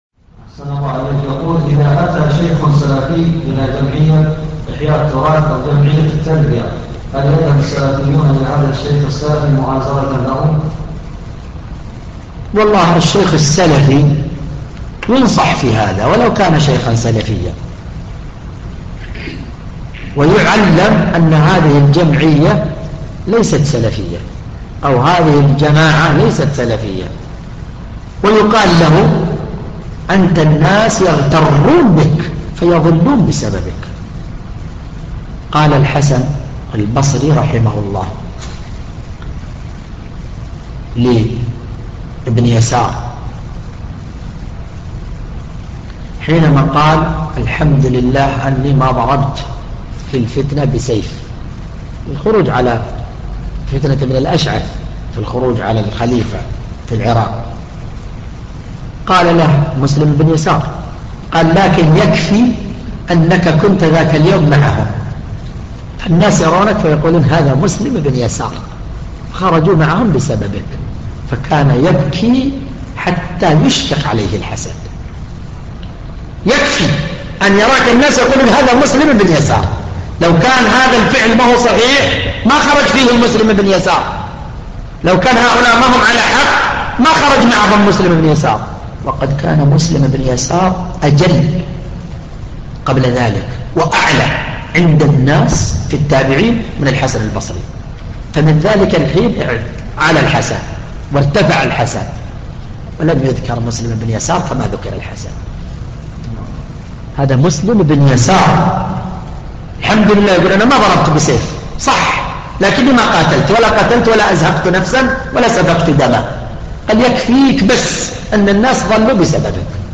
ملف الفتوي الصوتي عدد الملفات المرفوعه : 1